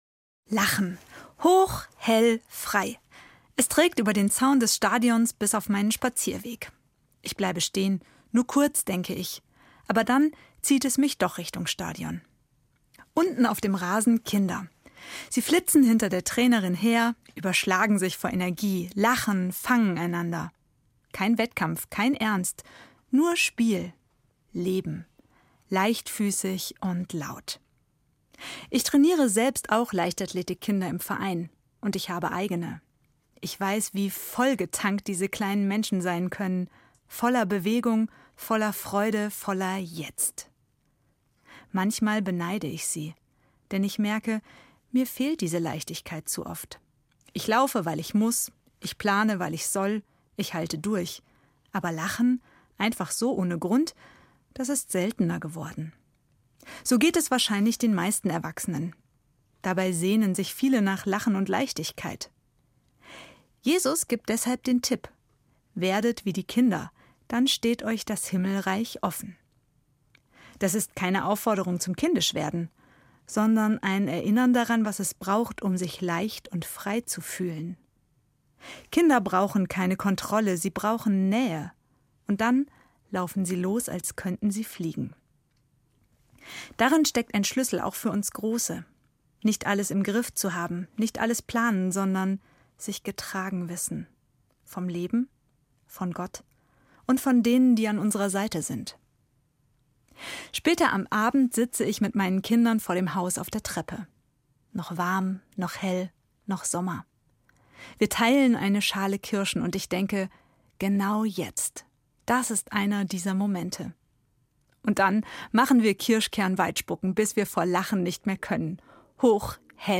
Ev. Pfarrerin in Fulda